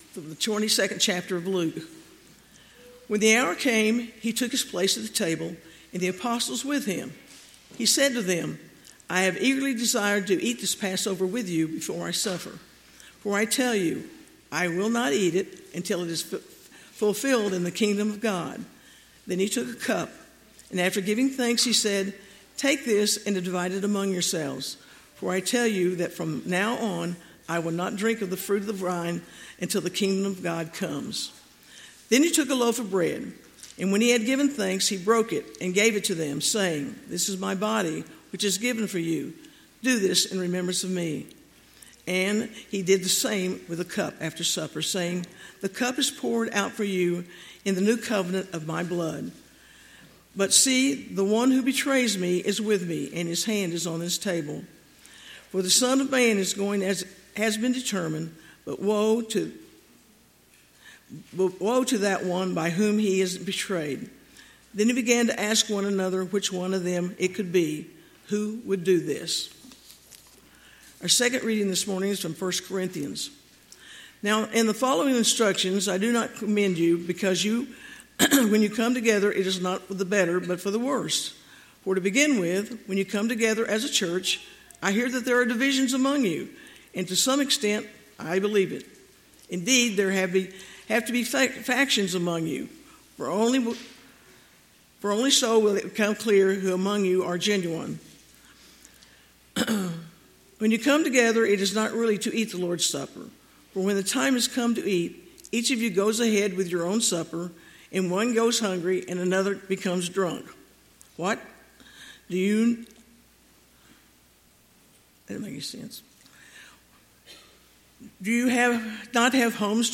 1 Corinthians 1:17-26 Service Type: Sunday Morning Topics